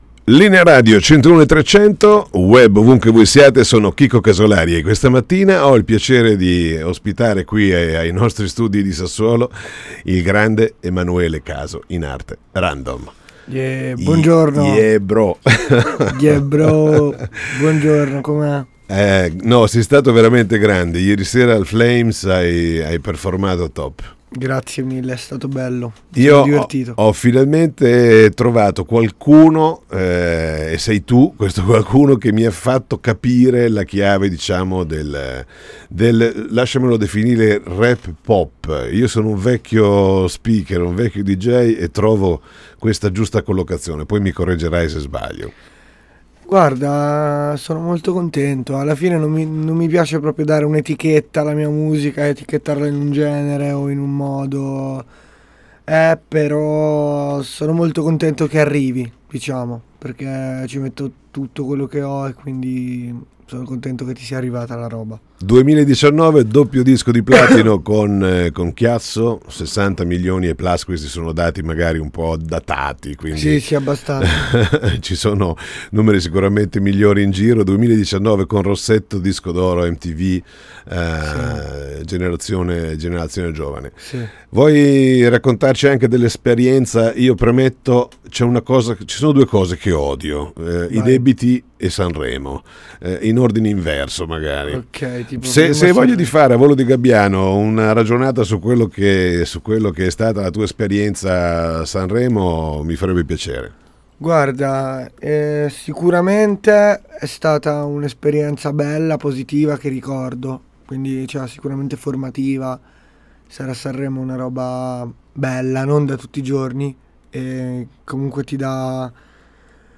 Intervista Random